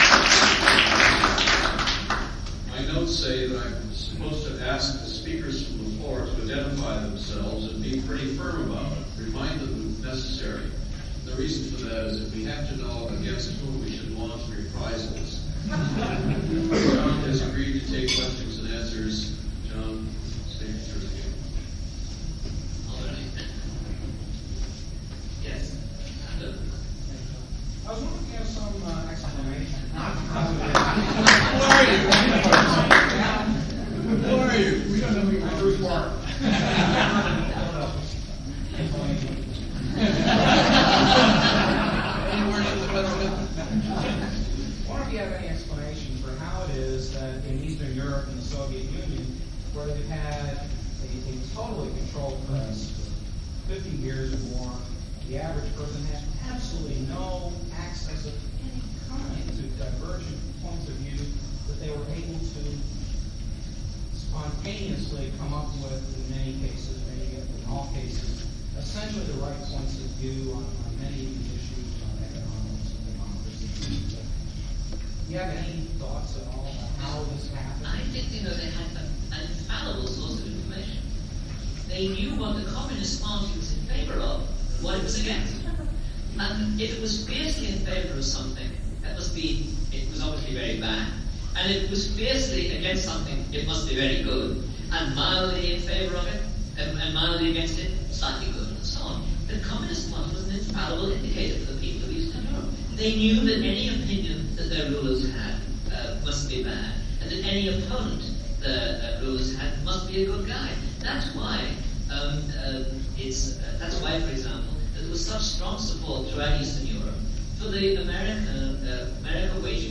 John O'Sullivan: Keynote Q & A